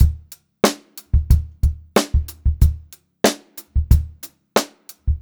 92ST2BEAT2-L.wav